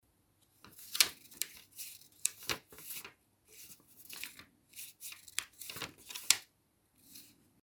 Звук купюр
Шелест долларовой купюры в руке
shelest-dollara.mp3